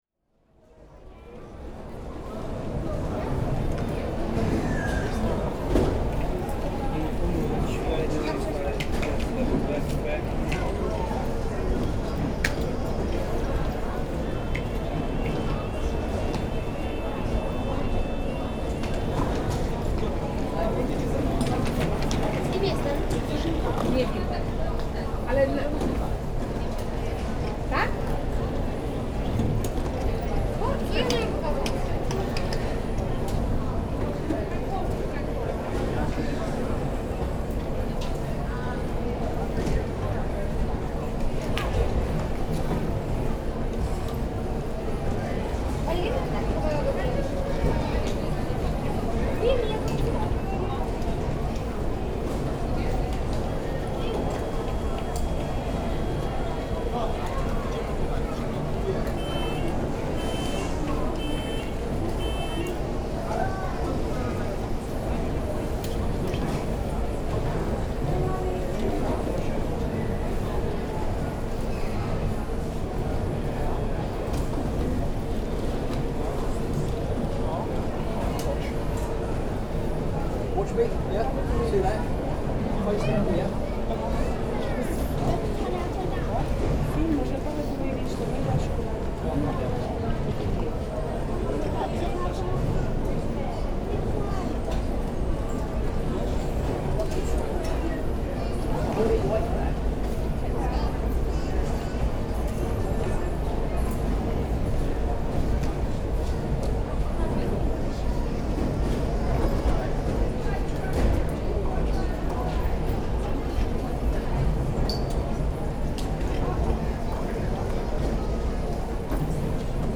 People going through the automatic departure gates
announcement (23)
Scene_109_Departure_Gates.mp3